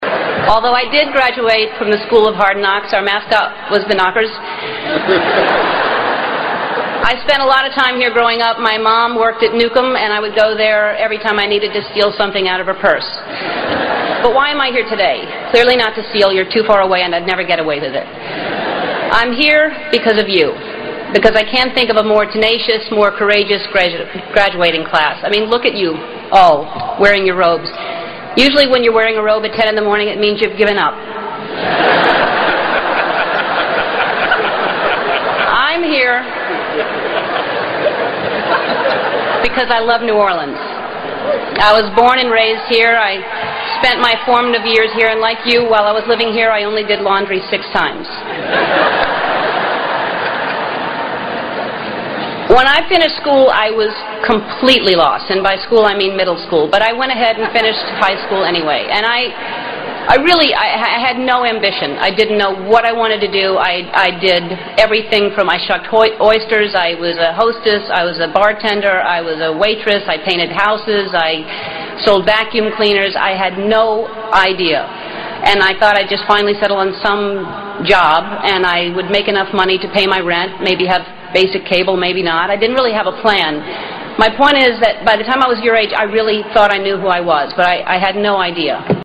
在线英语听力室偶像励志英语演讲 第80期:做真正的自己(2)的听力文件下载,《偶像励志演讲》收录了娱乐圈明星们的励志演讲。